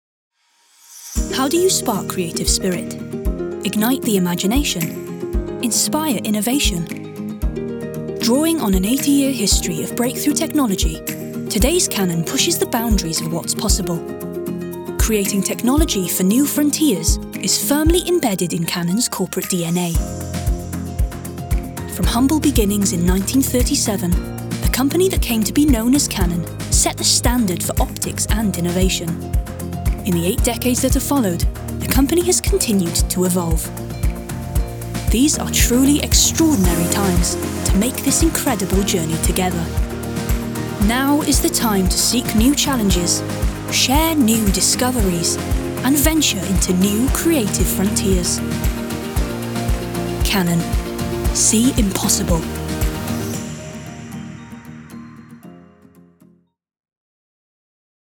Corporate Showreel
Non-binary
Friendly
Playful
Upbeat
Voice Next Door